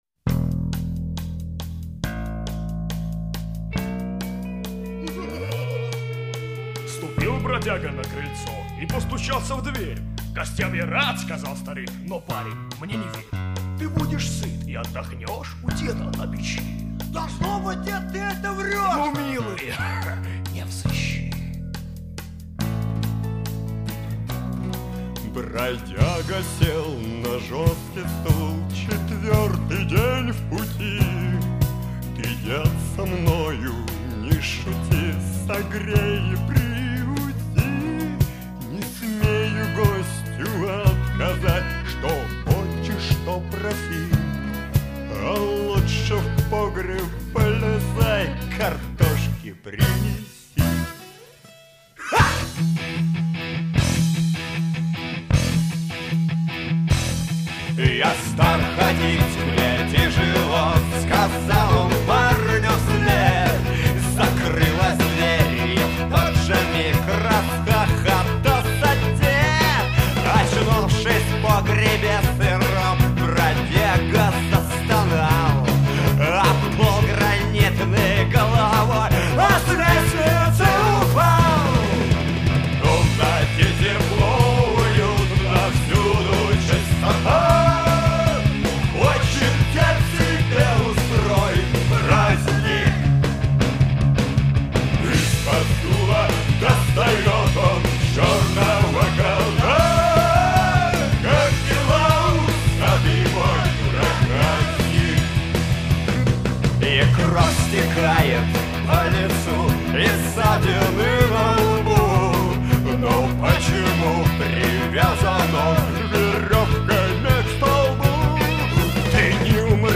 Главная » Онлайн Музыка » Рок